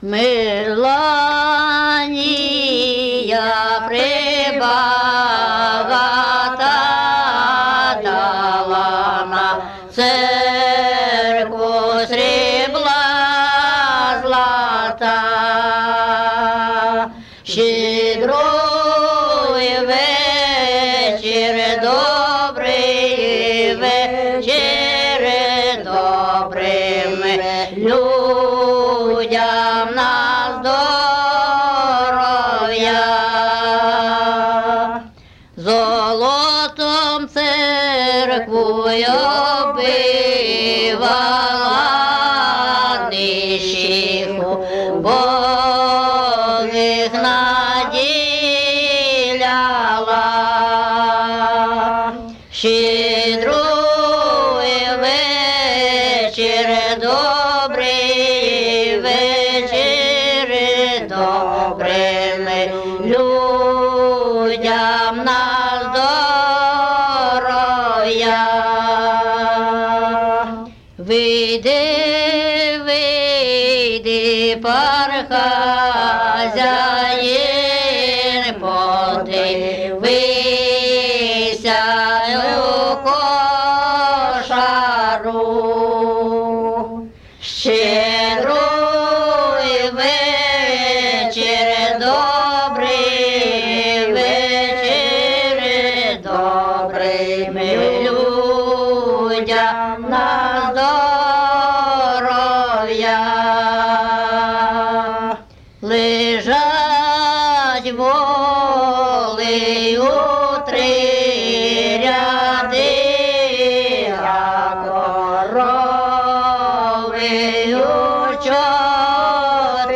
ЖанрЩедрівки
Місце записус. Ізюмське, Борівський район, Харківська обл., Україна, Слобожанщина